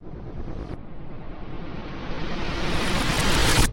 Звук флешбека